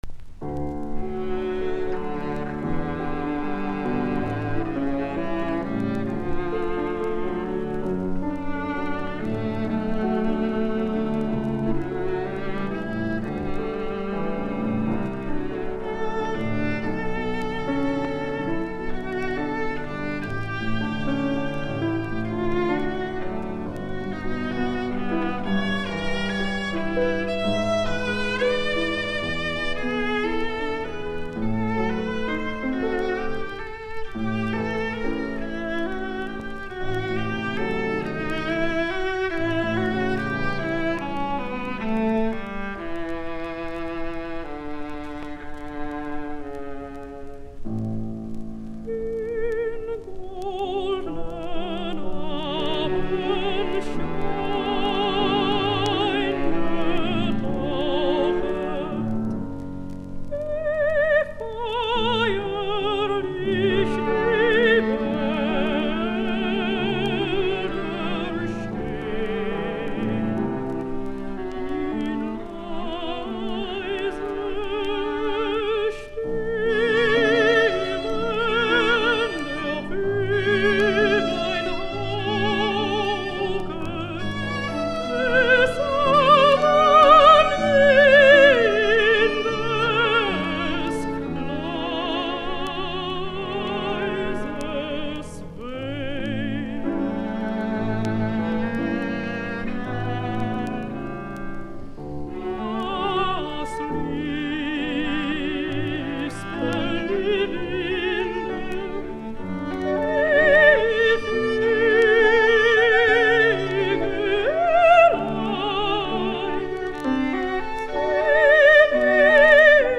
Laulut, A, alttoviulu, piano, op91
Soitinnus: Lauluääni (altto), alttoviulu, piano.